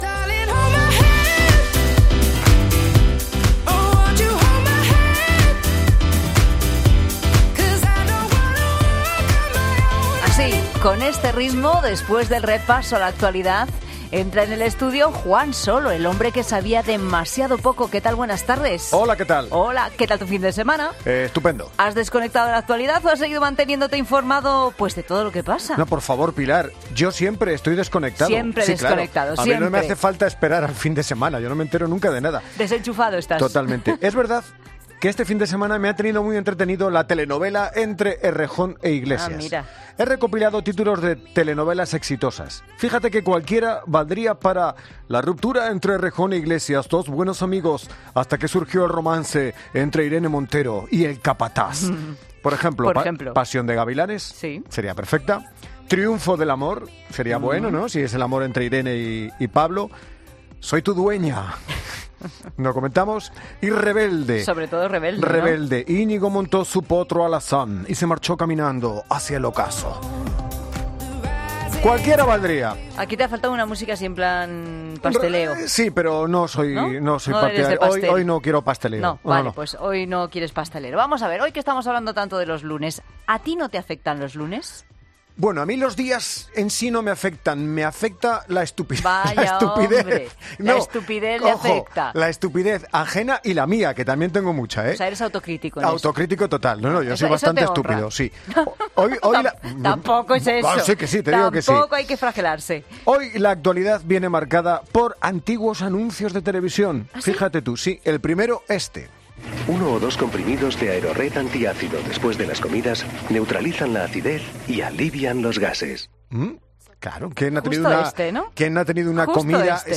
'La Tarde', un programa presentado por Pilar Cisneros y Fernando de Haro, es un magazine de tarde que se emite en COPE, de lunes a viernes, de 15 a 19 horas.